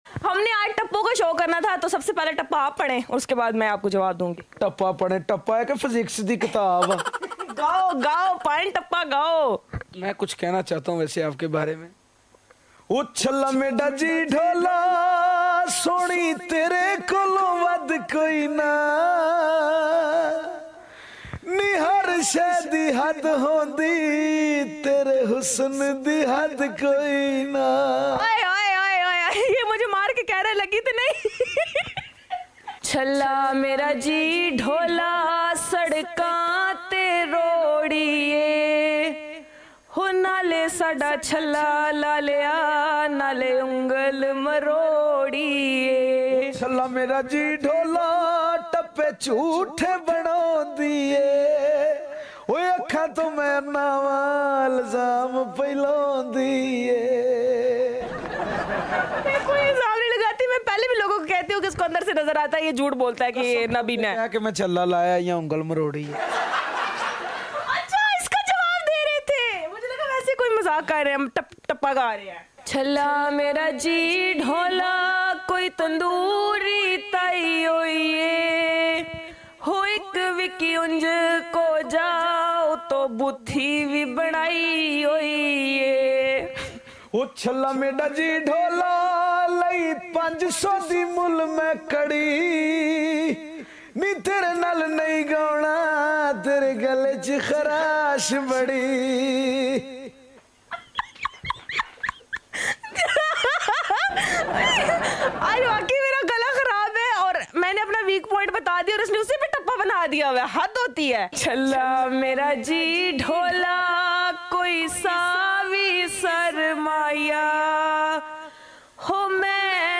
tappa muqabla singing competition